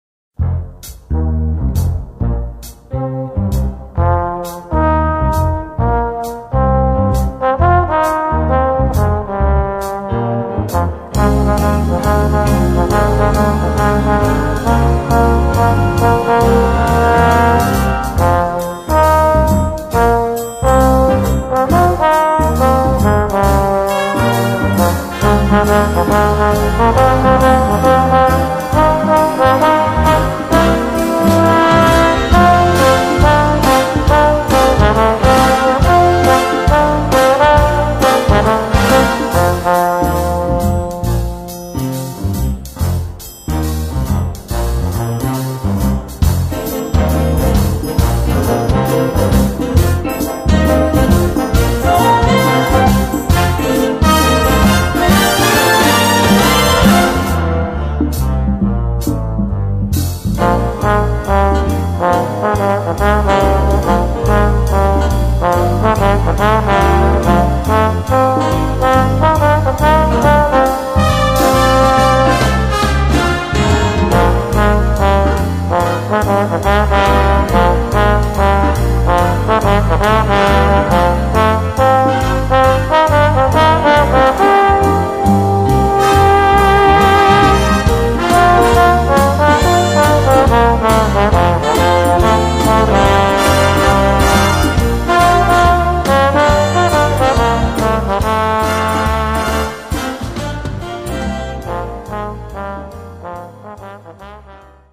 Voicing: Cornet and Brass Band